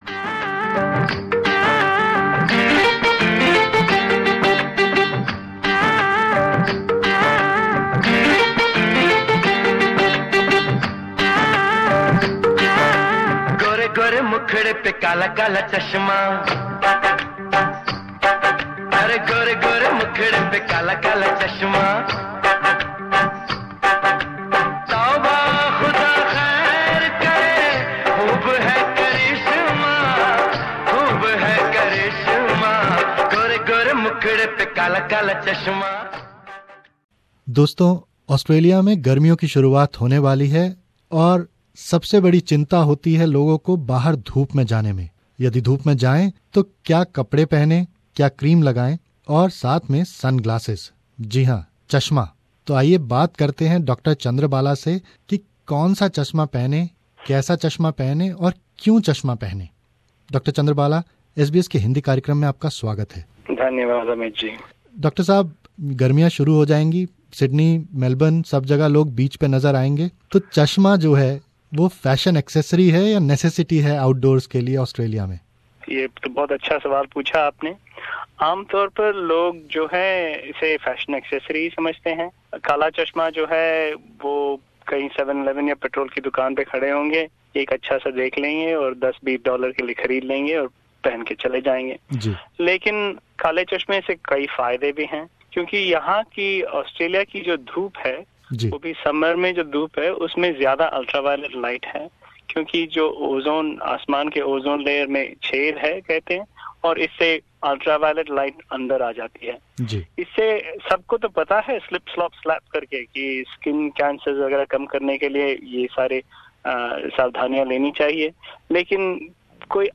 conversation